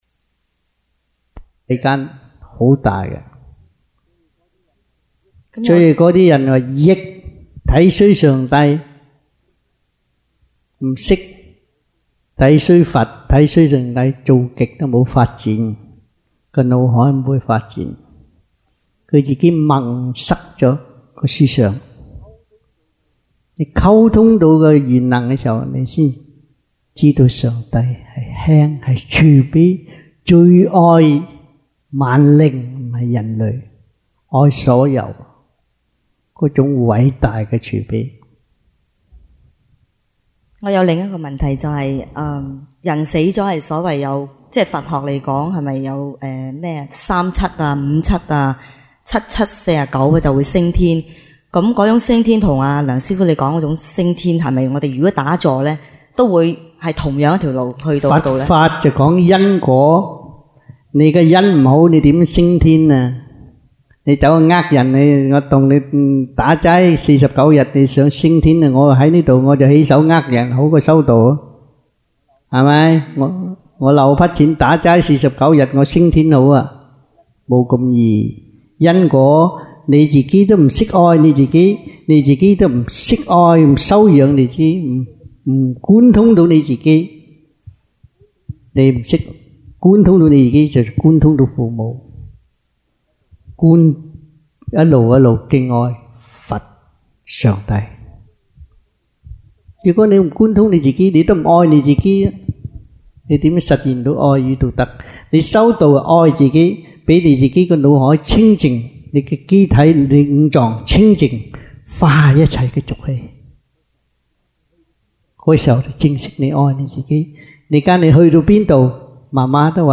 Q&A in Chinese-1993 (中文問答題)